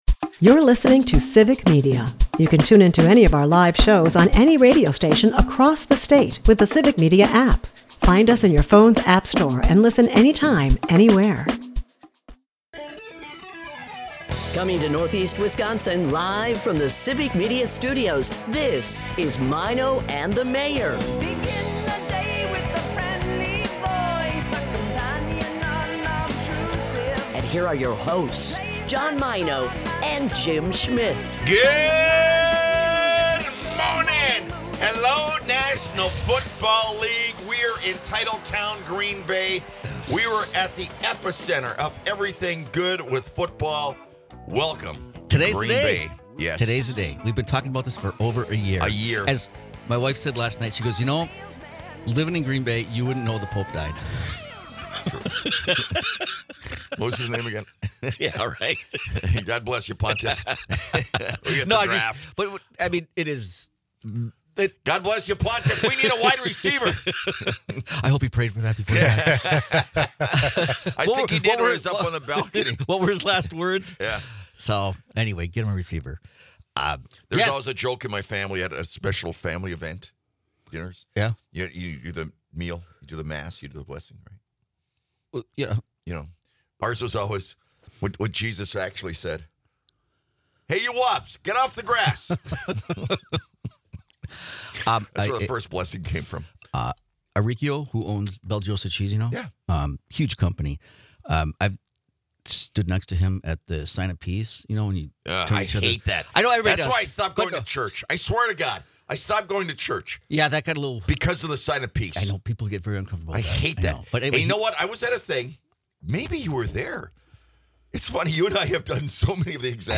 Former Green Bay Packer Frank Winters is today's special guest!